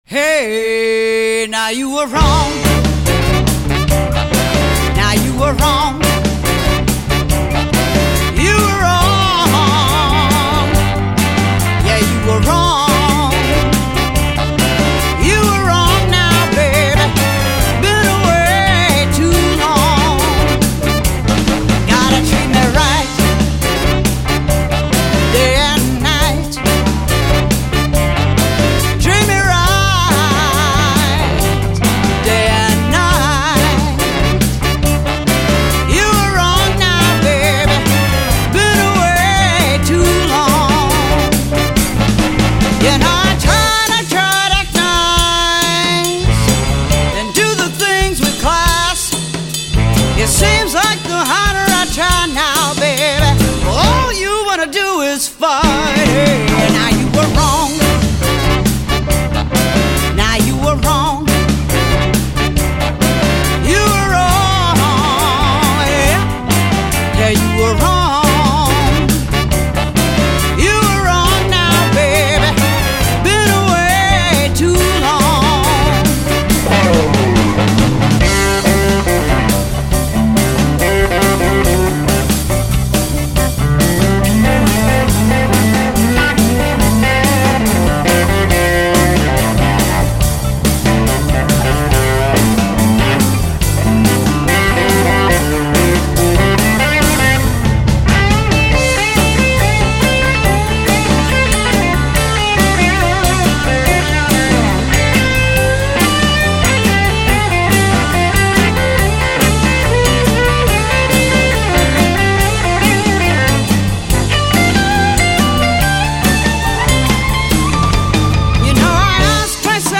джаз, ритм н блюз